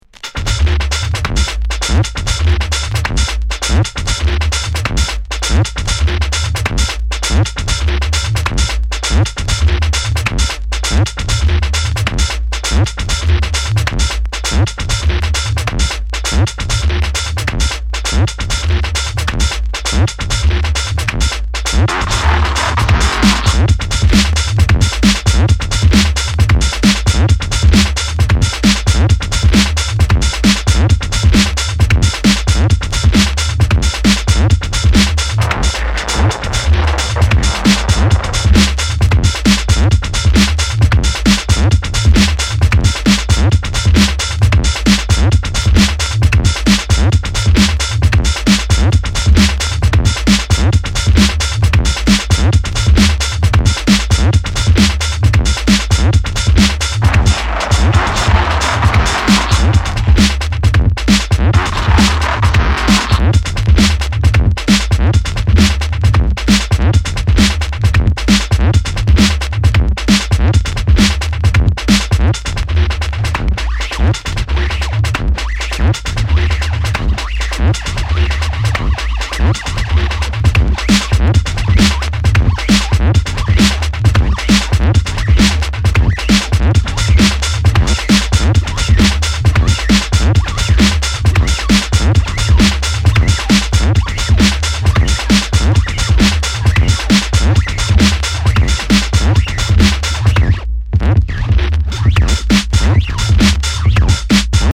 ディープテクノミニマル
バシっとしたスネアが印象的なアブストラクト・チューン